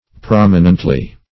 Prominently \Prom"i*nent*ly\, adv.